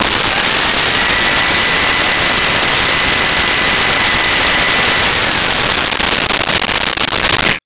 Blender On Liquify
Blender on Liquify.wav